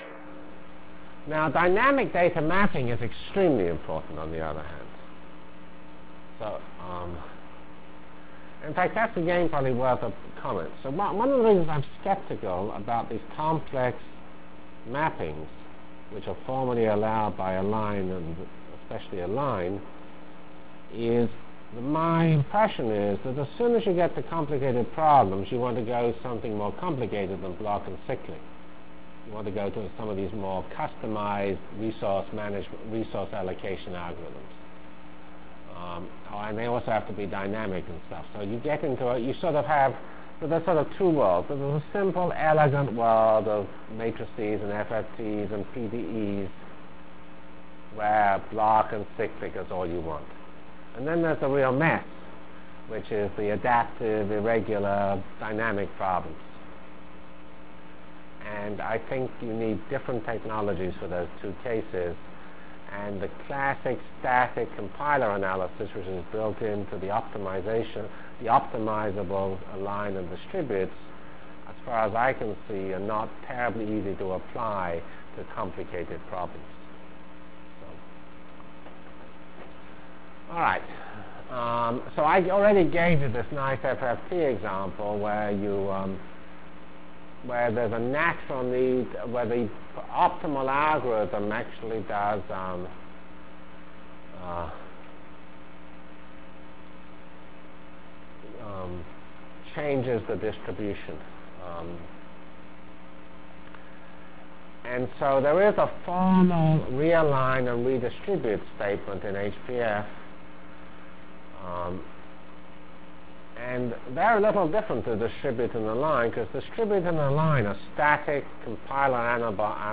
From CPS615-Align and Distribute in HPF Delivered Lectures of CPS615 Basic Simulation Track for Computational Science -- 1 October 96. by Geoffrey C. Fox *